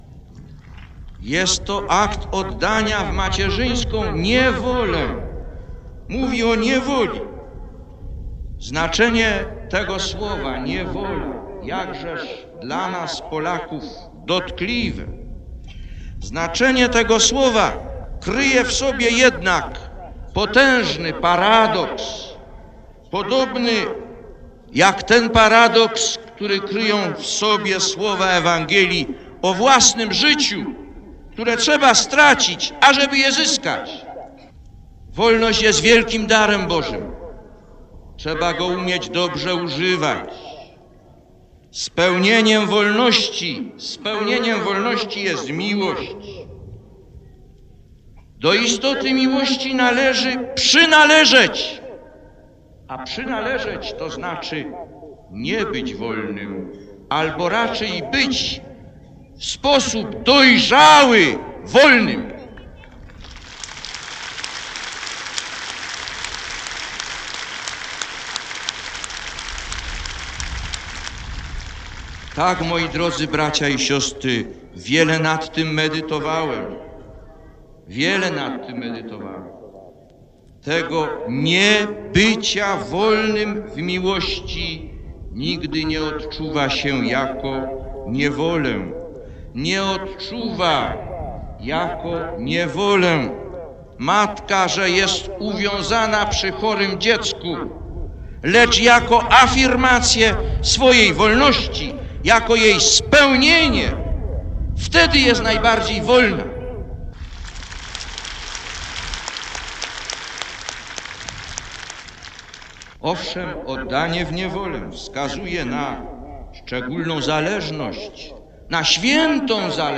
Lektor: Z homilii wygłoszonej w Częstochowie (4.06.1979 -